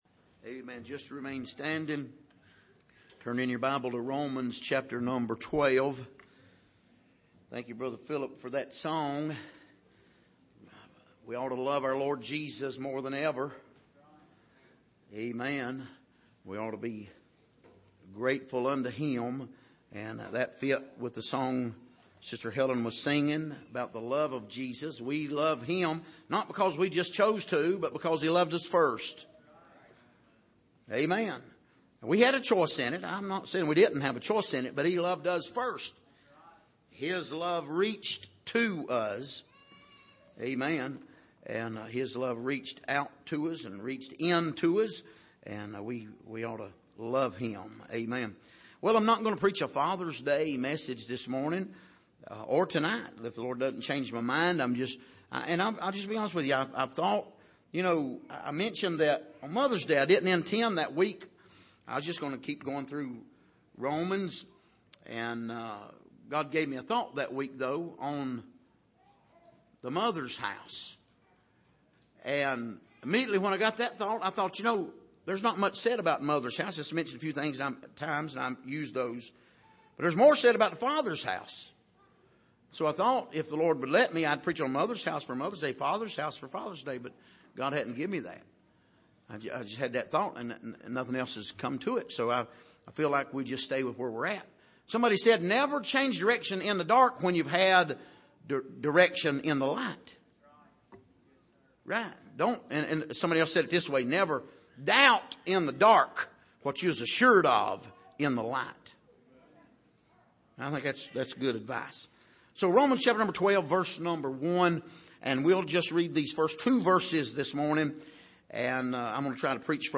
Passage: Romans 12:1-2 Service: Sunday Morning